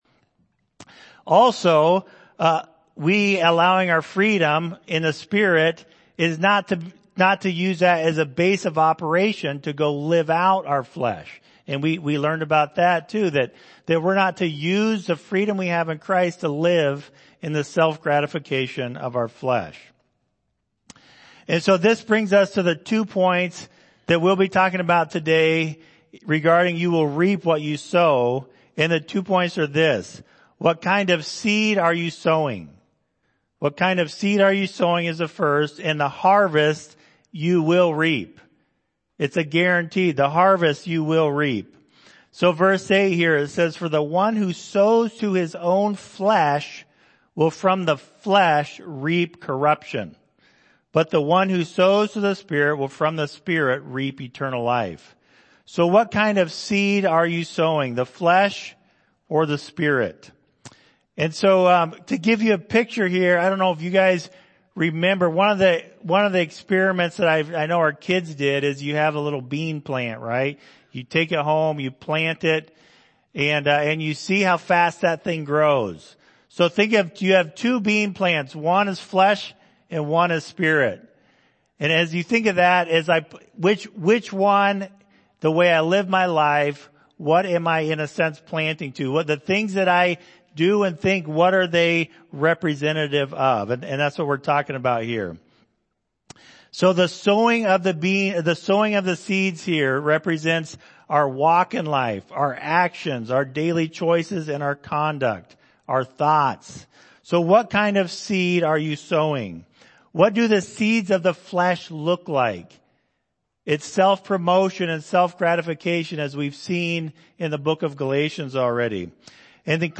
Galatians Passage: Galatians 6:6-8 Service Type: Sunday Morning « My Life in Step With the Spirit Today and That Day